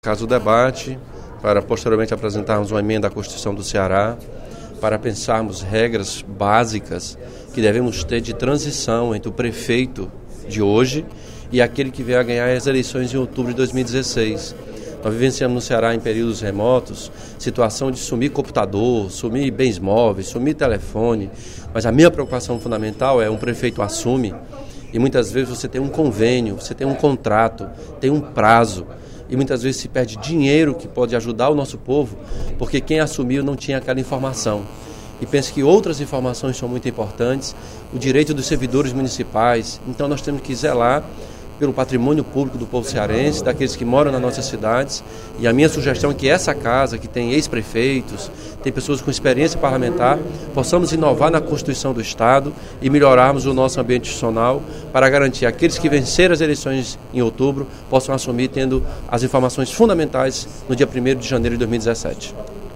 O deputado Elmano Freitas (PT) informou, no primeiro expediente da sessão plenária desta quinta-feira (25/02), que vai apresentar à apreciação do Poder Legislativo uma proposta de emenda constitucional determinando que os prefeitos que encerram seus mandatos sejam obrigados a repassar para a gestão que assumem informações e documentos relevantes para a administração. O propósito é combater desmonte da máquina pública e garantir a continuidade de contratos, obras e projetos iniciados na gestão anterior.